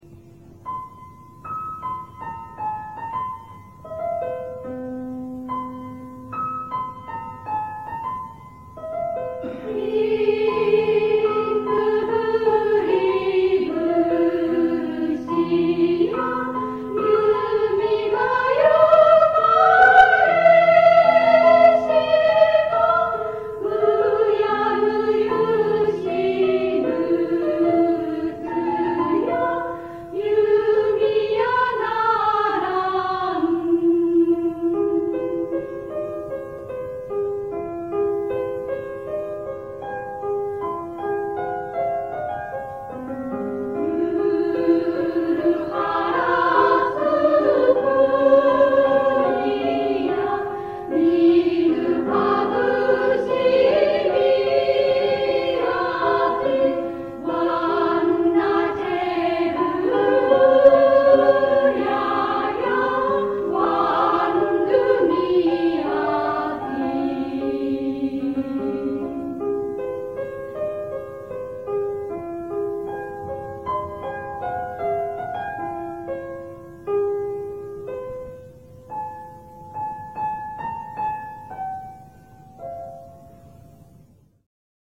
女声合唱団「道」 はじめてのコンサート
女声合唱団「道」のはじめてのコンサートを平成６年４月２４日（日）に開きました。